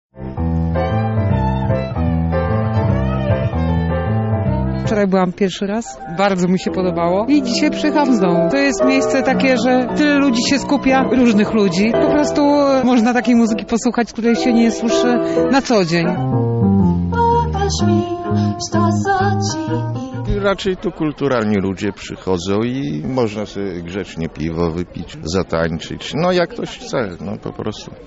To niecodzienna i wyjątkowa okazja do spotkania z taką muzyką – mówią widzowie koncertu.